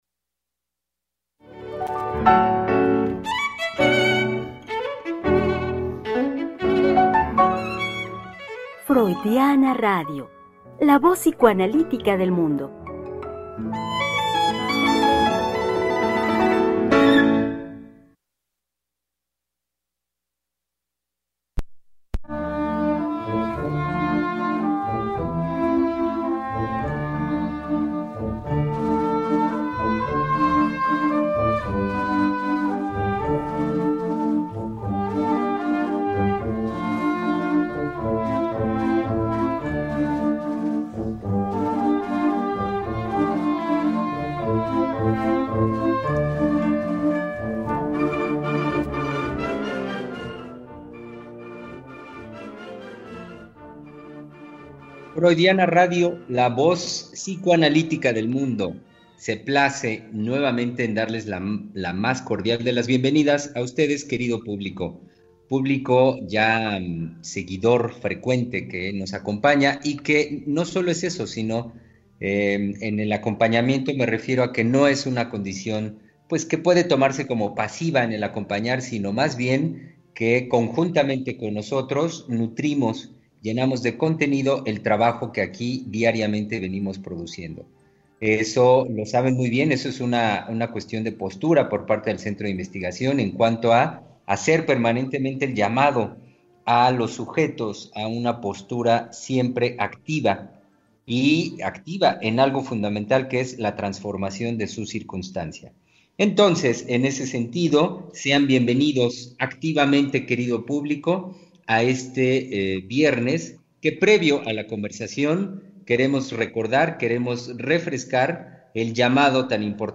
Conversación con nuestros invitados los psicoanalistas del CIEL.